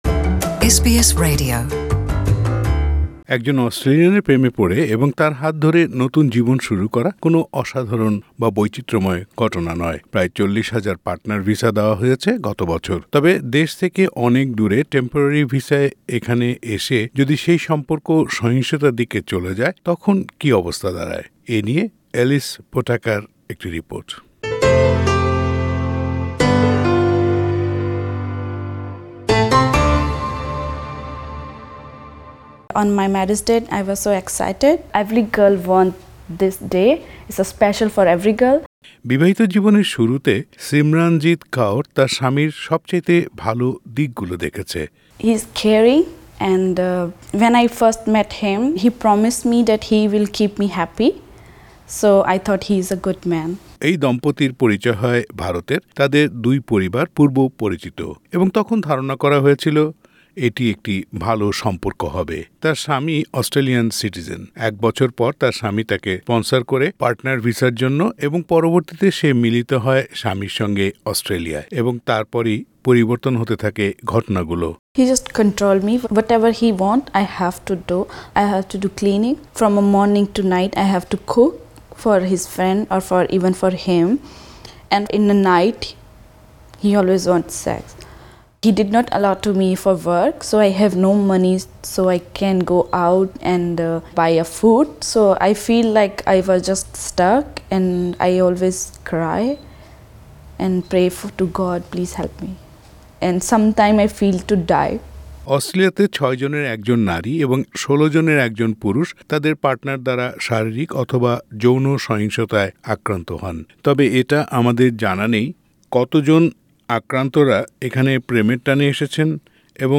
পার্টনার ভিসা ও পারিবারিক সহিংসতা নিয়ে পুরো প্রতিবেদনটি বাংলায় শুনতে উপরের অডিও প্লেয়ারটিতে ক্লিক করুন।